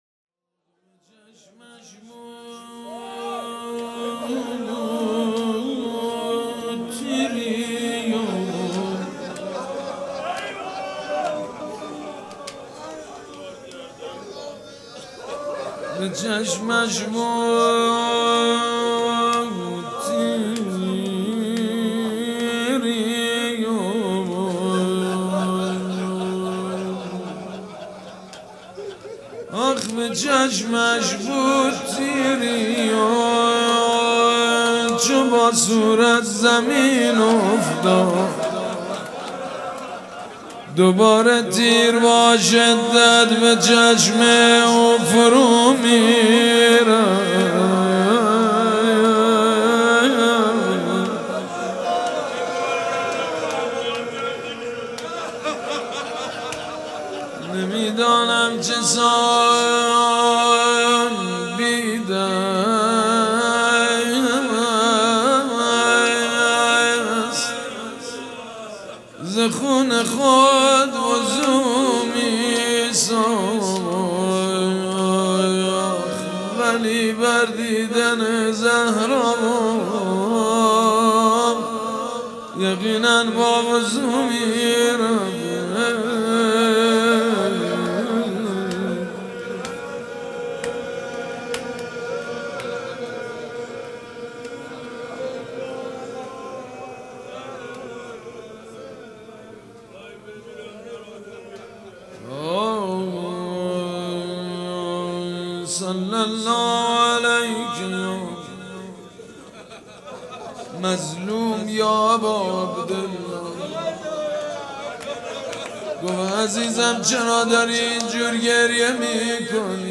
مراسم مناجات شب بیست و چهارم ماه رمضان
روضه